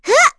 Erze-Vox_Attack2.wav